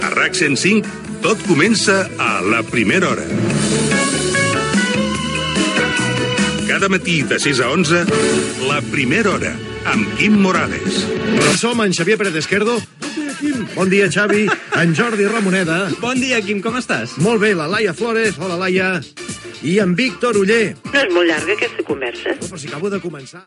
Careta del programa, equip
FM